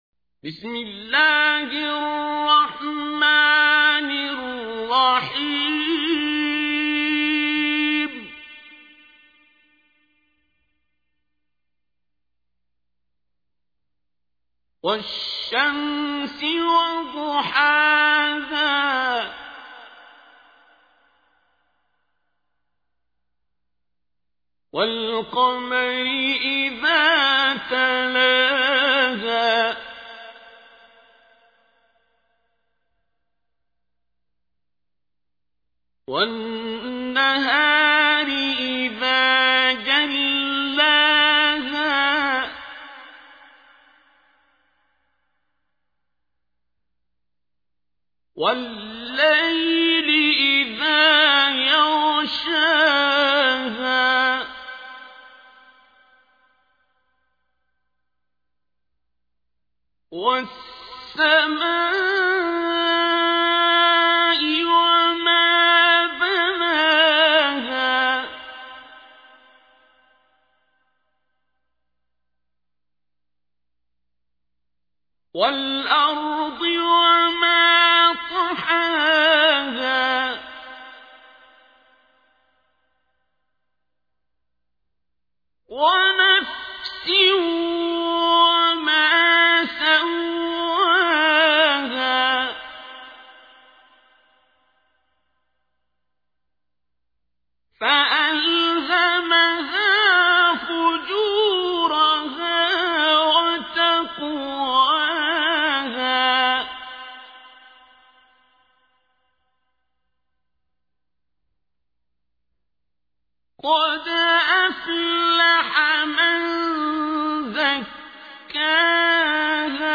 تحميل : 91. سورة الشمس / القارئ عبد الباسط عبد الصمد / القرآن الكريم / موقع يا حسين